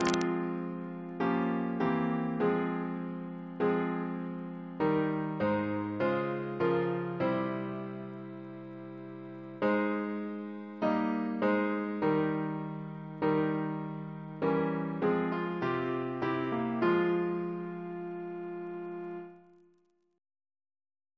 CCP: Chant sampler
Double chant in F minor Composer: Rev Luke Flintoft (1678-1727), Minor Canon of Westminster Abbey Reference psalters: ACB: 46; ACP: 127; CWP: 27; H1982: S239; OCB: 120; PP/SNCB: 51; RSCM: 52